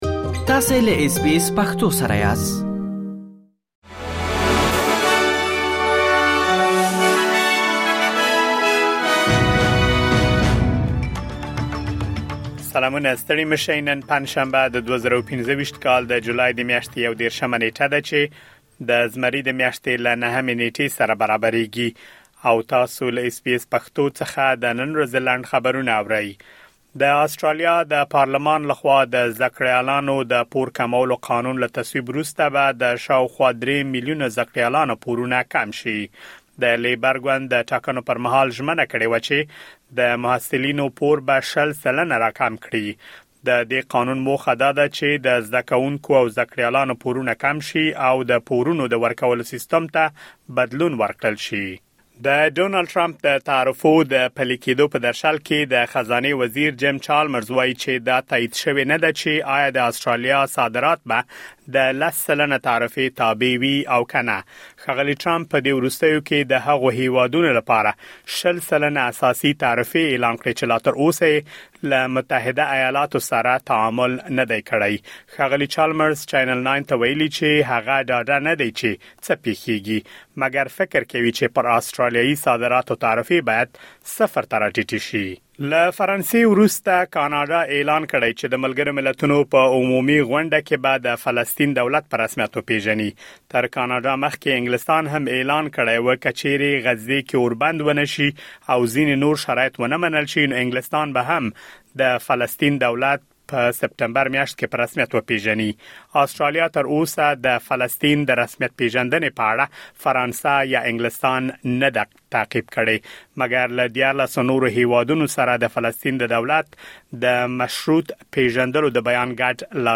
د اس بي اس پښتو د نن ورځې لنډ خبرونه |۳۱ جولای ۲۰۲۵